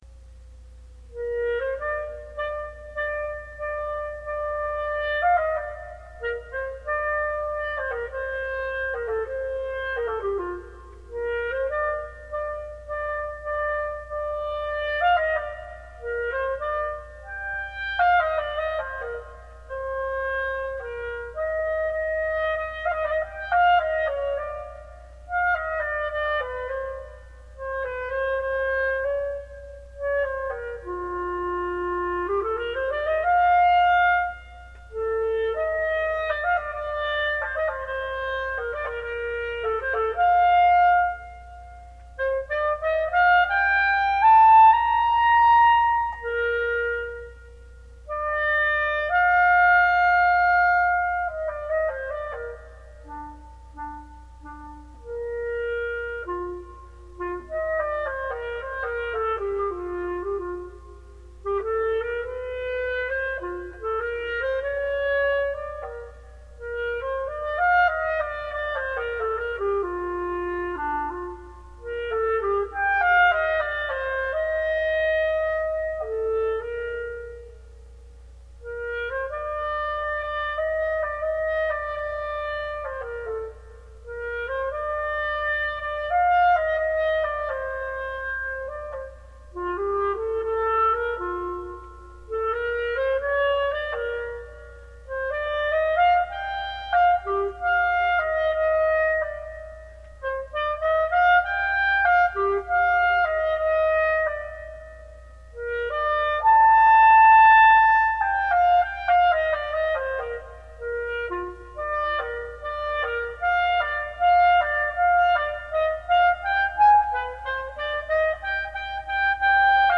Classical solo clarinet.